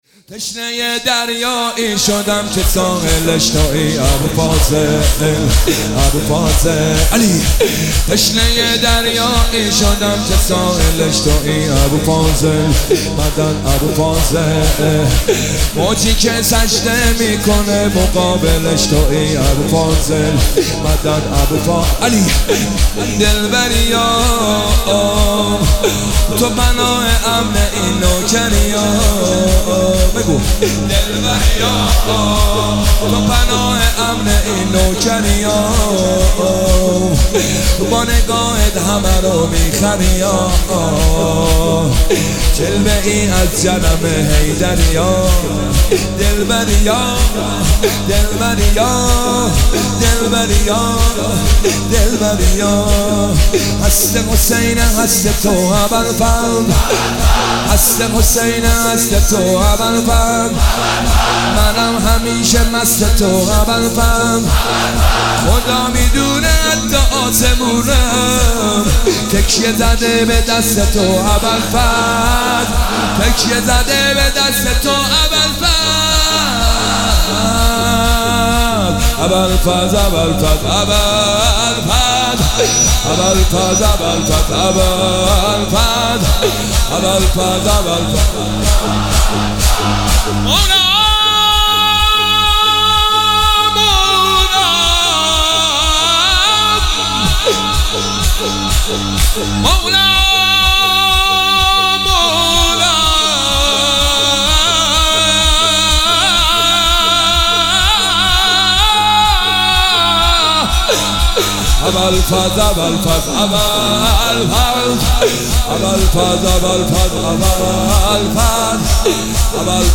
محفل عزاداری شب نهم محرم